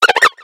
Cri de Flamajou dans Pokémon X et Y.